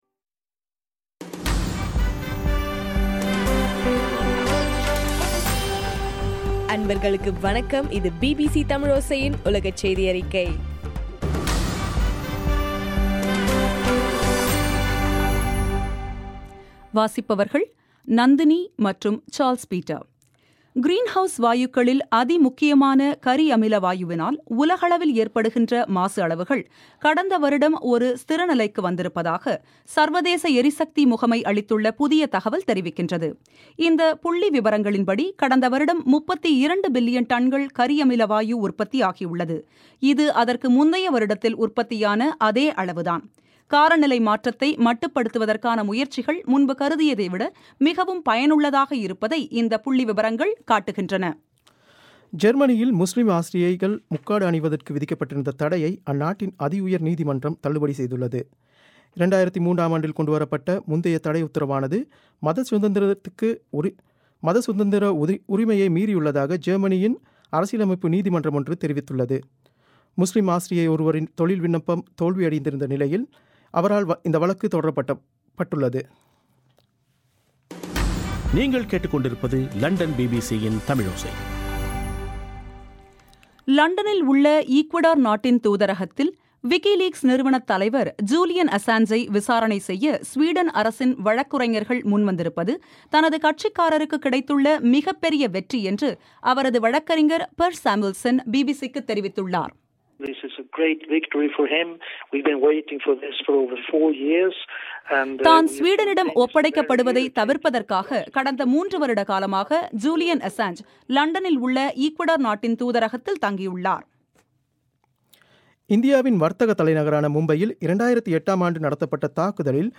இன்றைய ( மார்ச் 13) பிபிசி தமிழோசை செய்தியறிக்கை